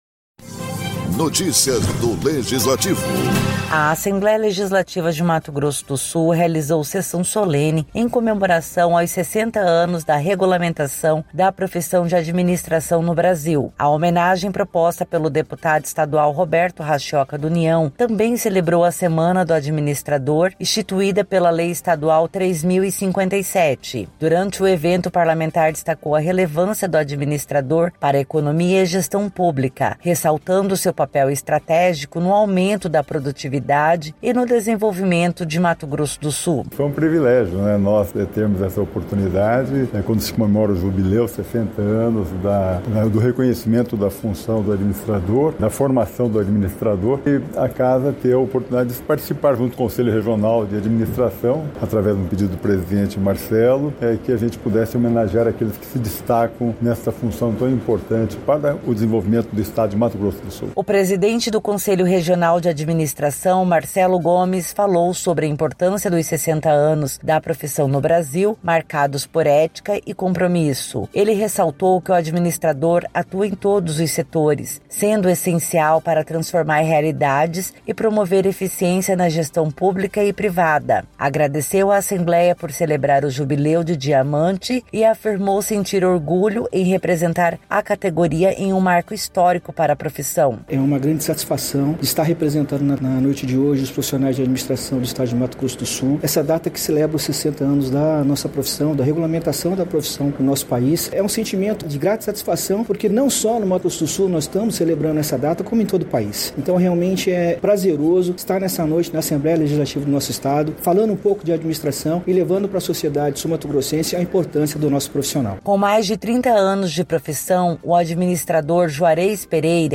A Assembleia Legislativa de Mato Grosso do Sul (ALEMS) realizou uma Sessão Solene em comemoração ao Jubileu de Diamante da regulamentação da profissão de Administração no Brasil.